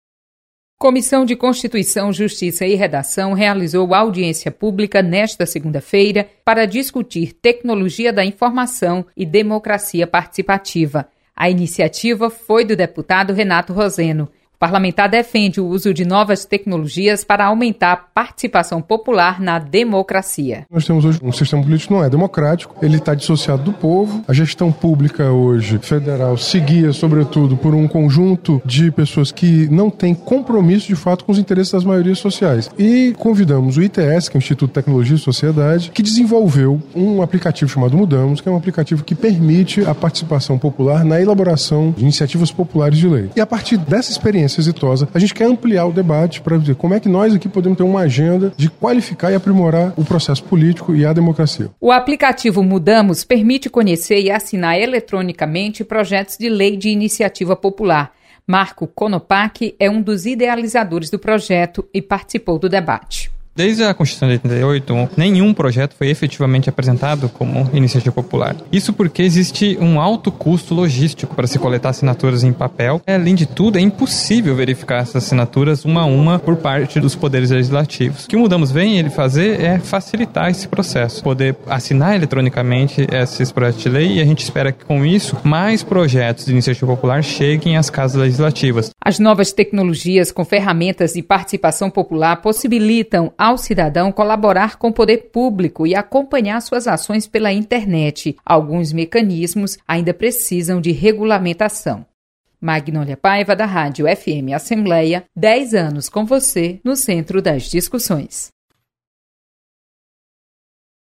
Deputado sugere inclusão do Queremos Deus no calendário oficial de eventos do Estado. Repórter